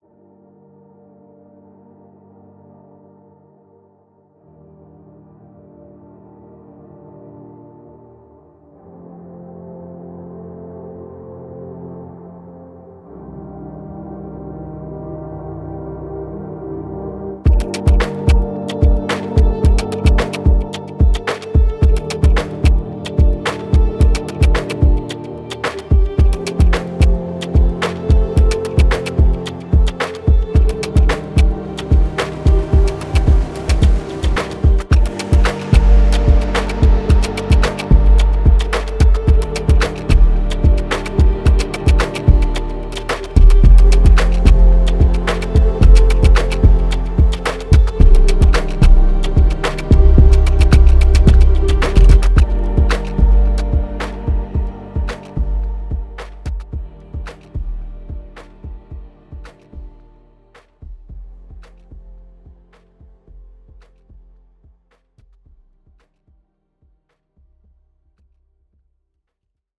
Genre:Electronica
これは、夜の雰囲気を探求するアトモスフェリックエレクトロニカの旅です。
デモサウンドはコチラ↓